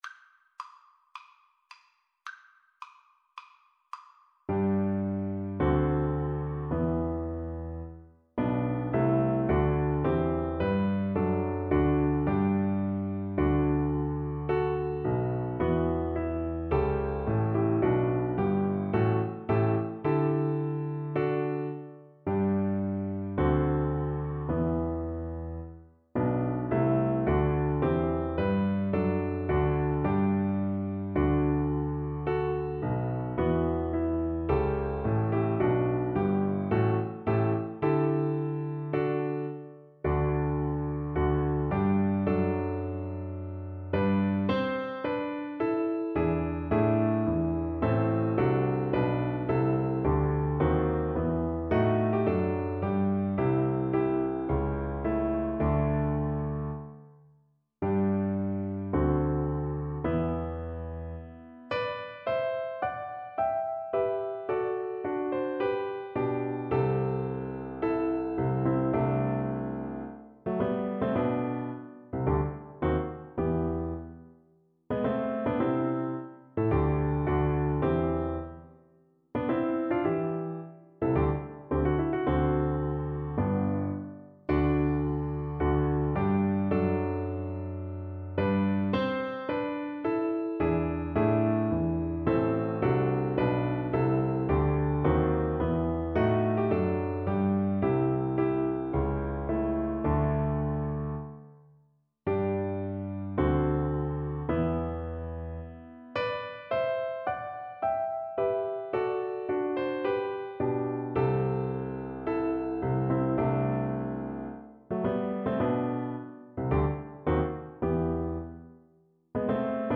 Classical Mozart, Wolfgang Amadeus March of the Priests from The Magic Flute Soprano (Descant) Recorder version
Play (or use space bar on your keyboard) Pause Music Playalong - Piano Accompaniment Playalong Band Accompaniment not yet available transpose reset tempo print settings full screen
Recorder
2/2 (View more 2/2 Music)
G major (Sounding Pitch) (View more G major Music for Recorder )
Andante = c.54
Classical (View more Classical Recorder Music)